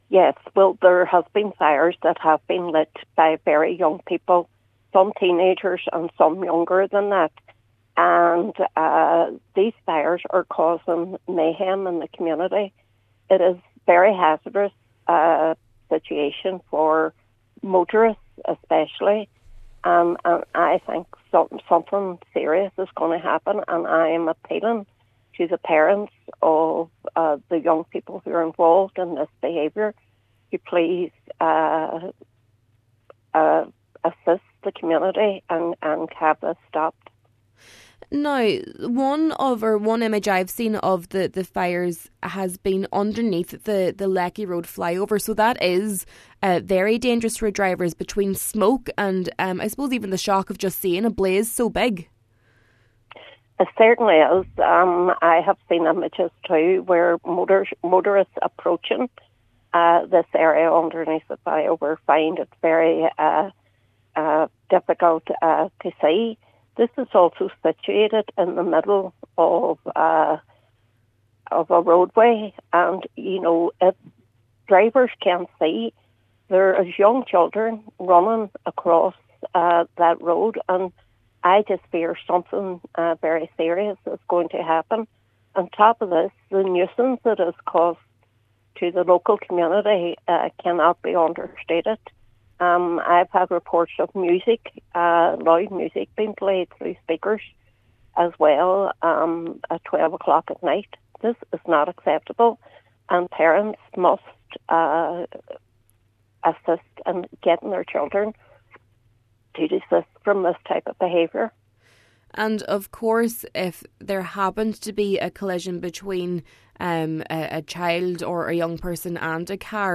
Cllr Logue said it’s her understanding that those involved are particularly young and made this direct appeal to them: